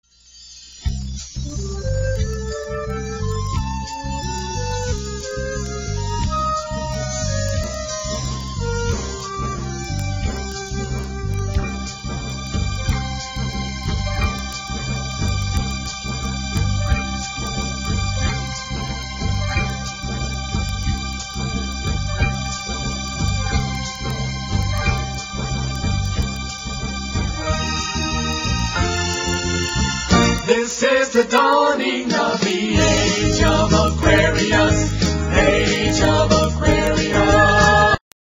NOTE: Background Tracks 7 Thru 12